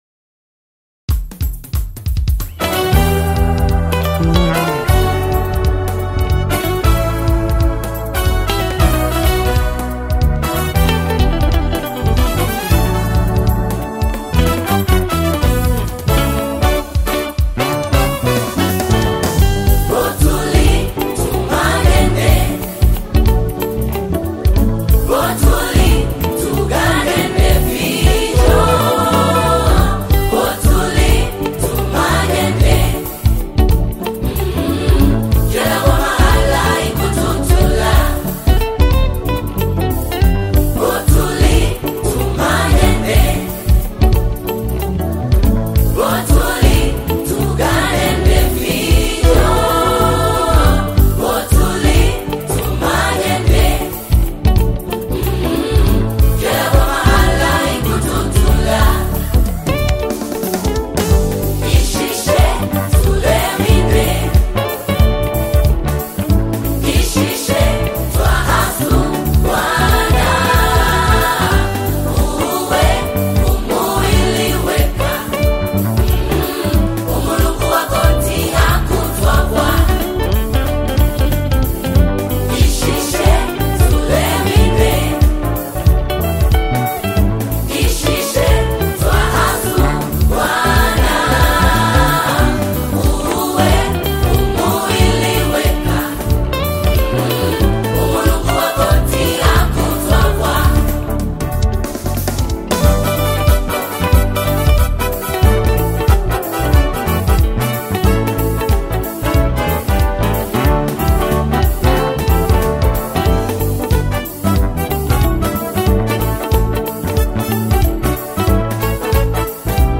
Tanzanian gospel choir
gospel song